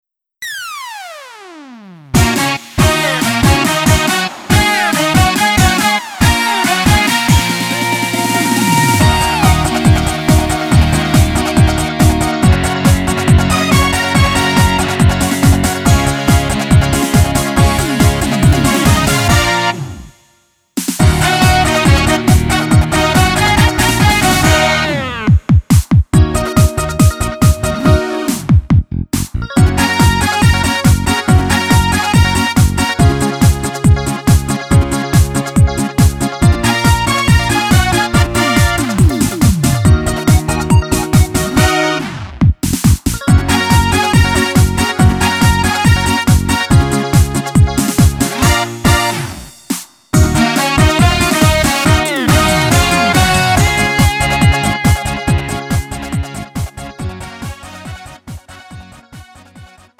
음정 -1키 3:17
장르 가요 구분 Lite MR